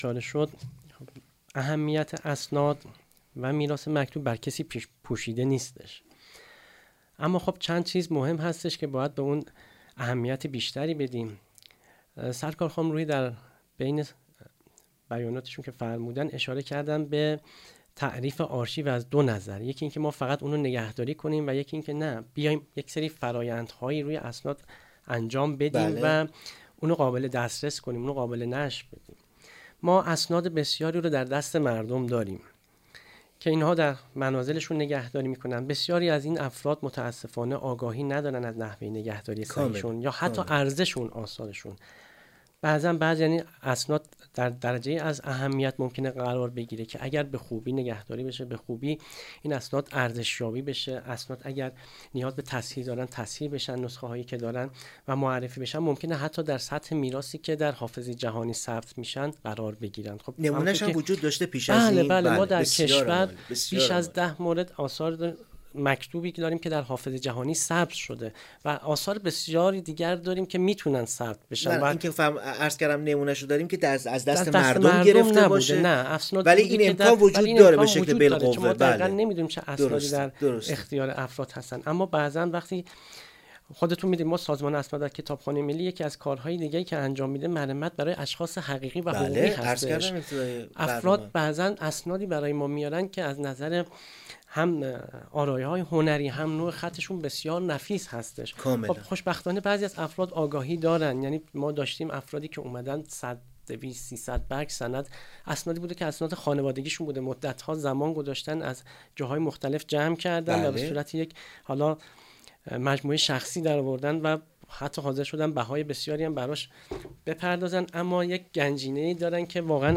میزگرد ایکنا به مناسبت روز اسناد ملی و میراث مکتوب/ 2